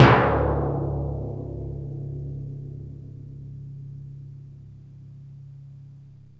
TIMP 1.WAV